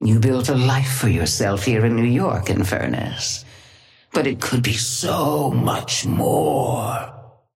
Sapphire Flame voice line - You built a life for yourself here in New York, Infernus, but it could be so much more.
Patron_female_ally_inferno_start_05.mp3